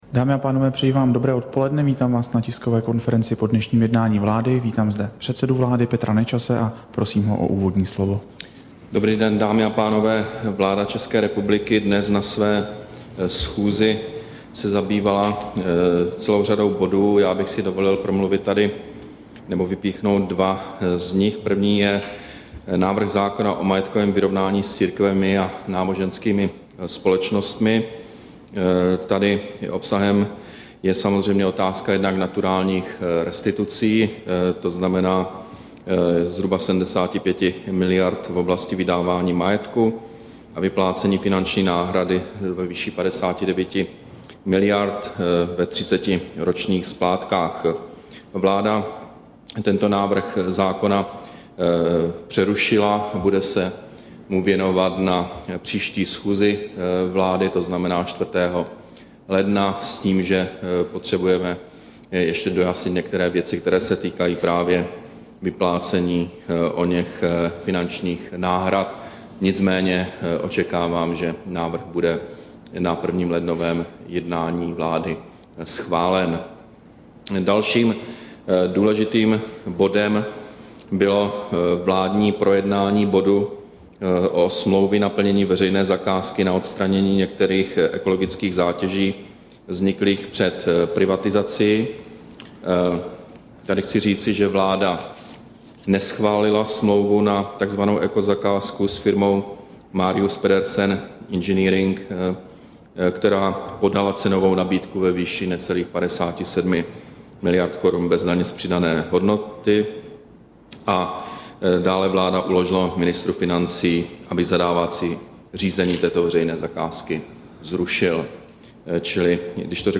Tisková konference po jednání vlády, 21. prosince 2011